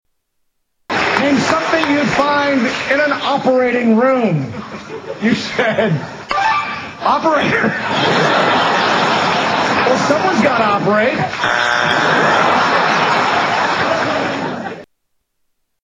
Tags: Media Worst Game show answers in History Television Stupid Answers Game Shows